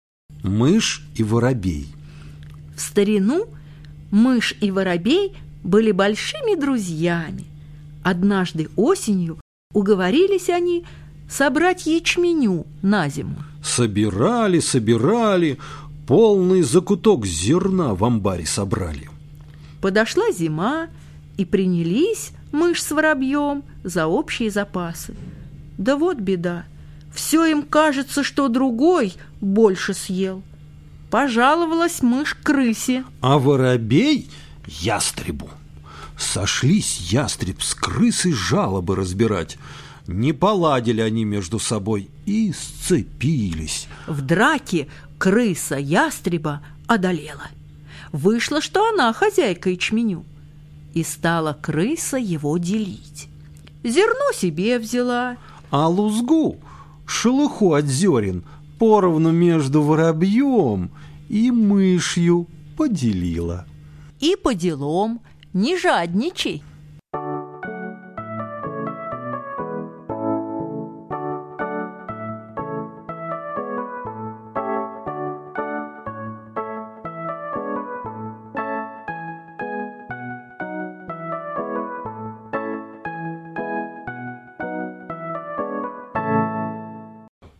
Мышь и воробей - латышская аудиосказка.